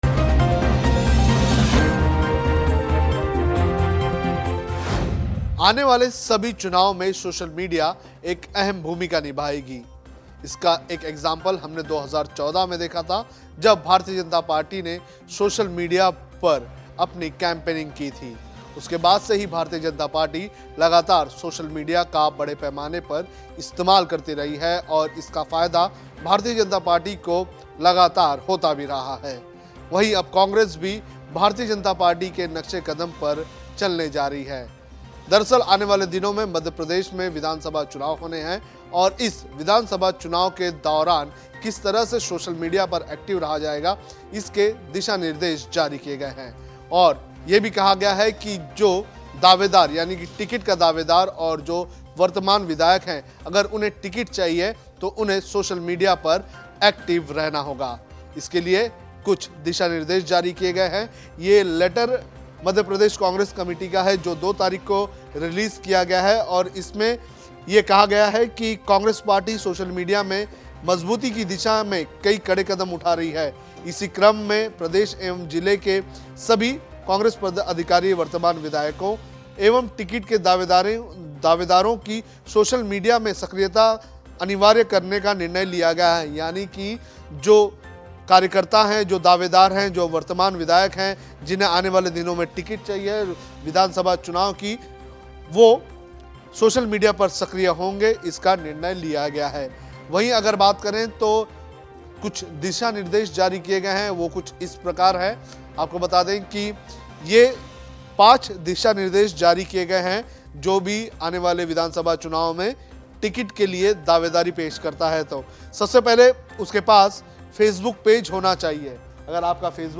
न्यूज़ रिपोर्ट - News Report Hindi / सोशल मीडिया पर एक्टिव नहीं तो नहीं मिलेगी कांग्रेस टिकट